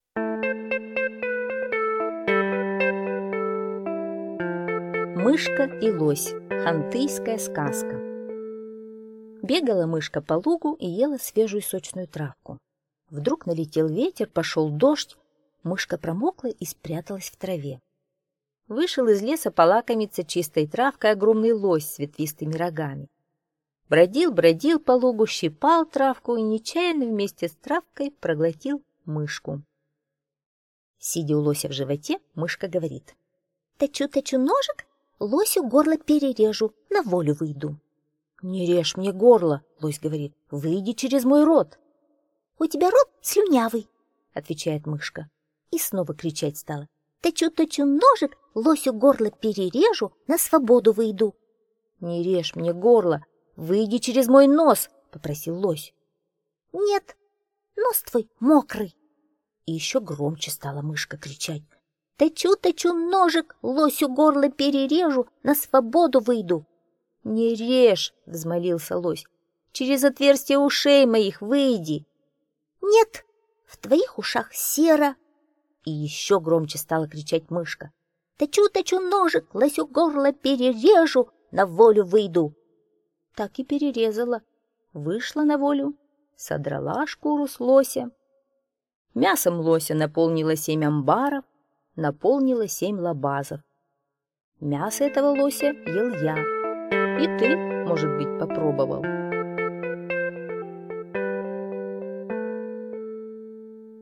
Мышка и лось - хантыйская аудиосказка - слушать онлайн